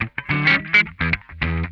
CRUNCHWAH 5.wav